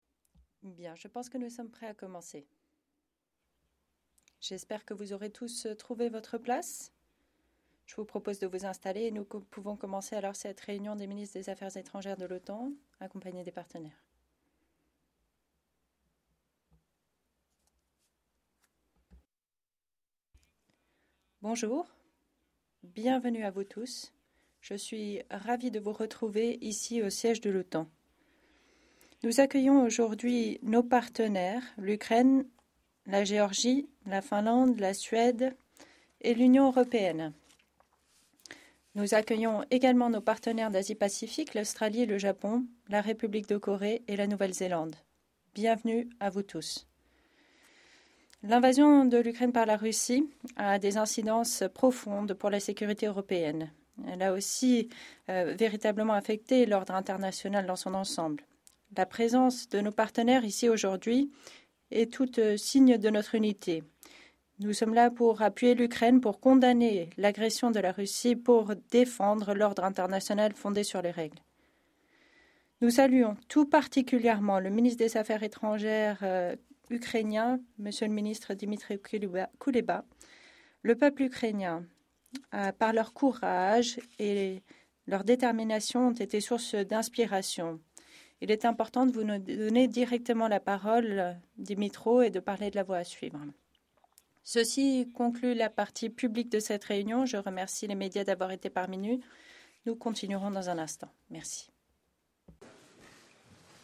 ENGLISH - Opening remarks by NATO Secretary General Jens Stoltenberg at the meeting of the North Atlantic Council in Foreign Ministers session, with the participation of partners